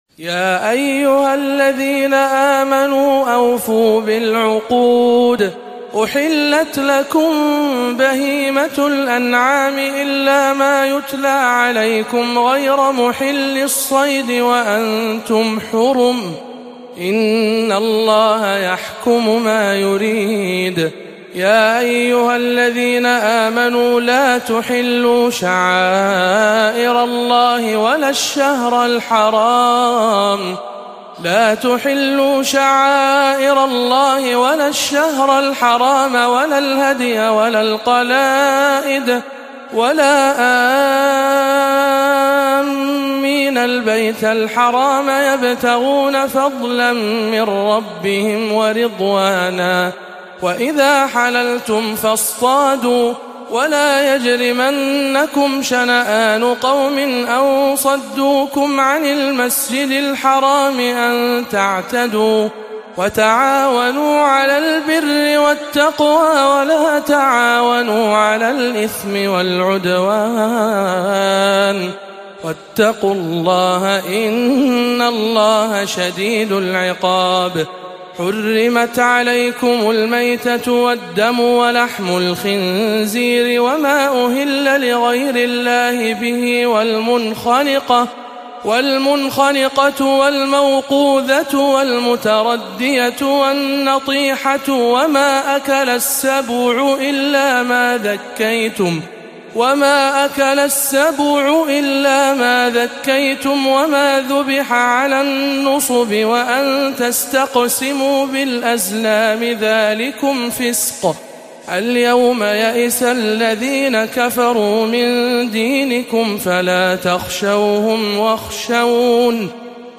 03. سورة المائدة بجامع أم الخير بجدة - رمضان 1438 هـ